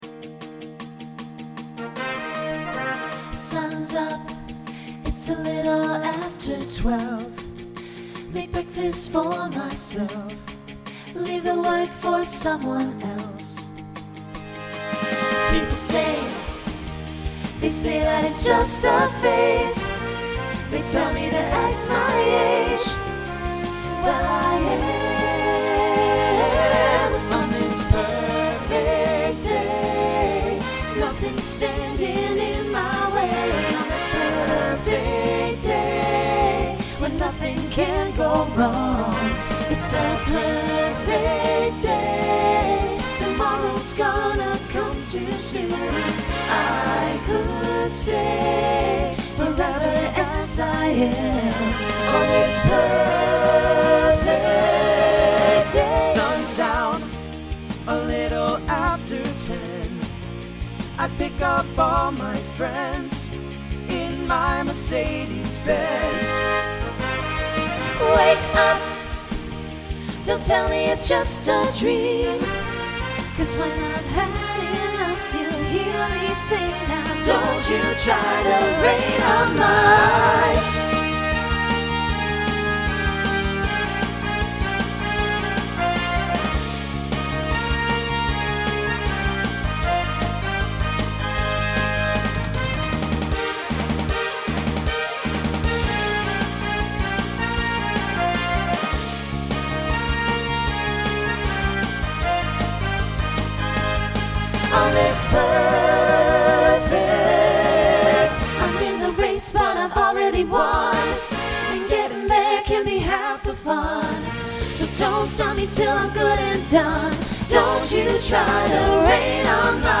New SSA voicing for 2026.